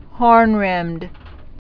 (hôrnrĭmd)